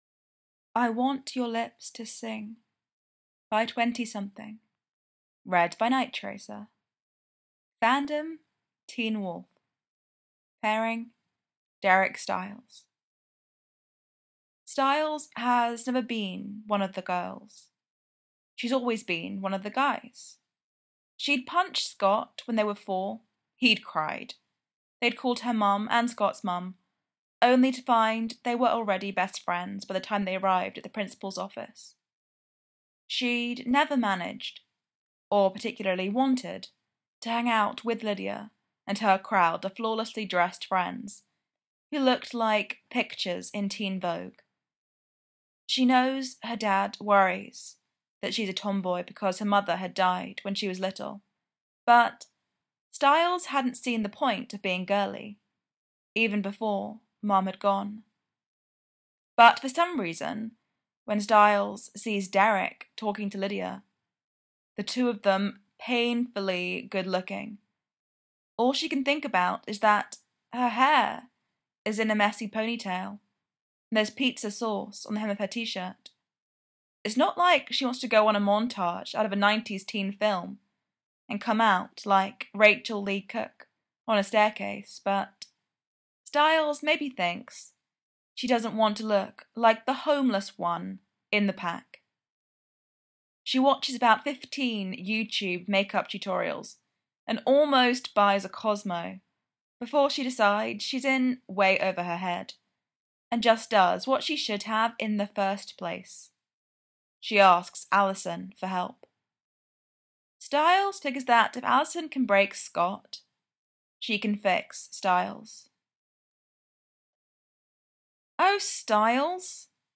teen wolf, derek/stiles, podfic